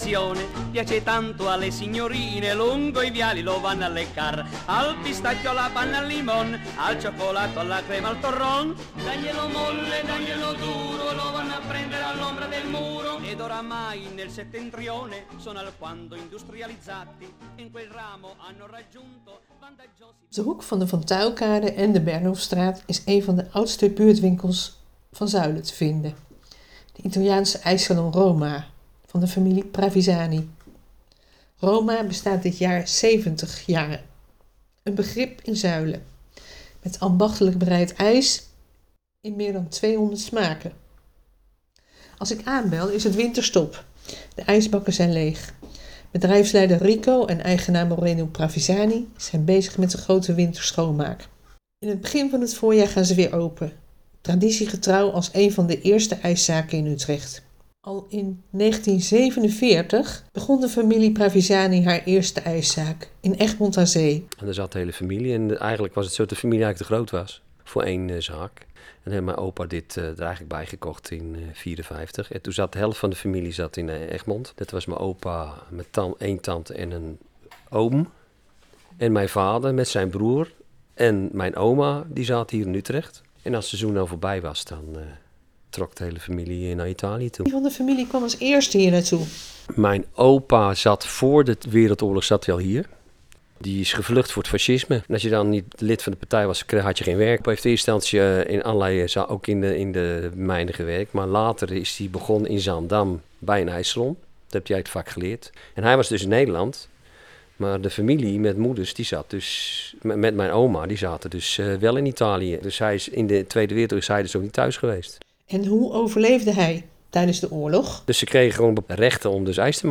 interviewt